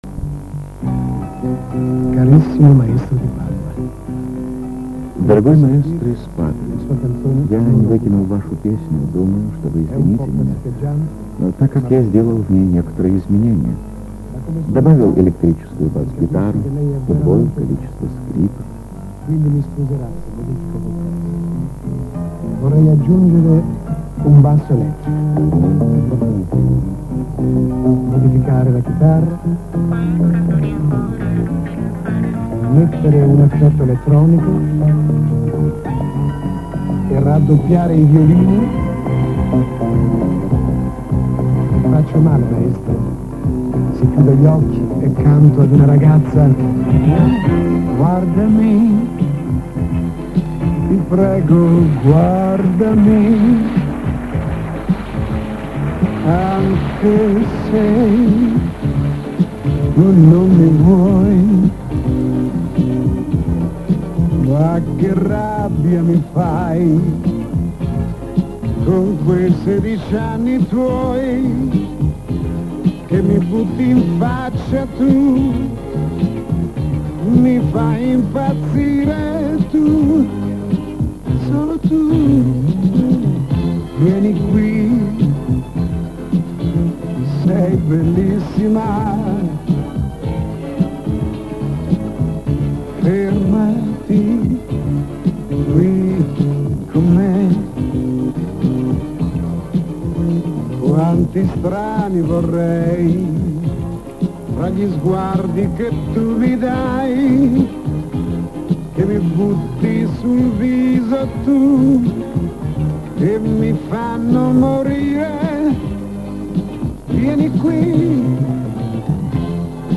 Запись по звукам с концерта, но с какого?
Слышен комментарий Виктора Татарского.